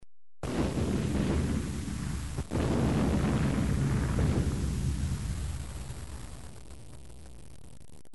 دانلود صدای بمب و موشک 5 از ساعد نیوز با لینک مستقیم و کیفیت بالا
جلوه های صوتی